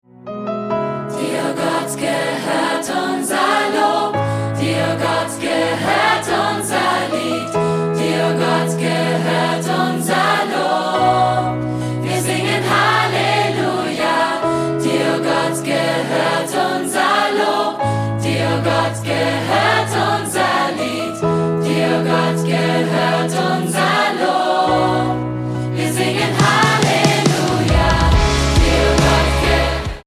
100 Sänger und Live-Band